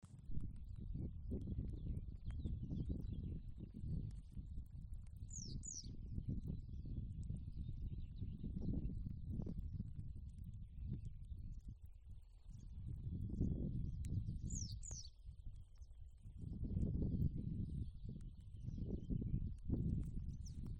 Dzeltenā cielava, Motacilla flava
StatussUzturas ligzdošanai piemērotā biotopā (B)
Piezīmes/pļavā, ik pa laikam uzsēžas uz gana mietiem